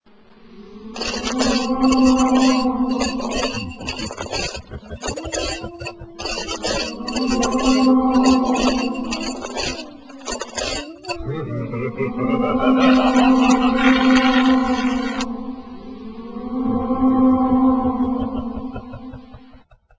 ts_ghost.wav